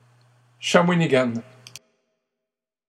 Shawinigan (English: /ʃəˈwɪnɪɡən/; French: [ʃawiniɡan]